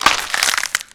break4.ogg